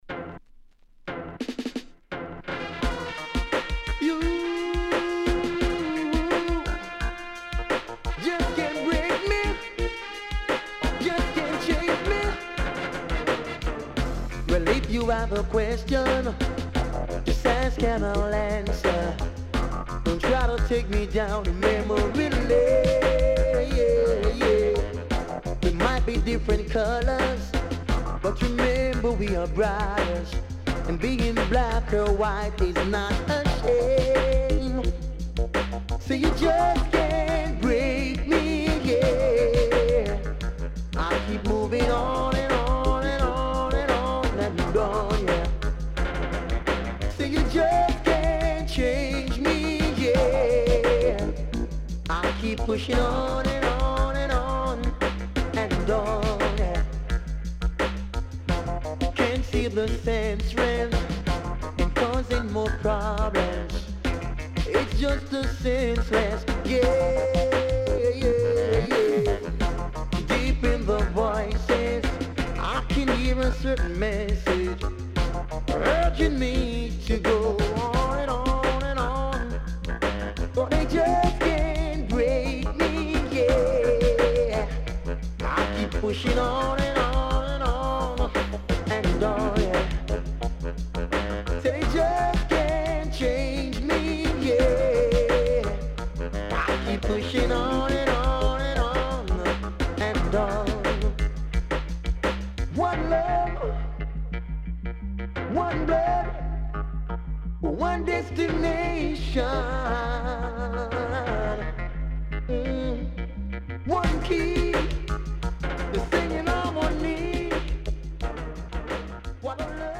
HOME > LP [DANCEHALL]
SIDE B:少しチリノイズ入りますが良好です。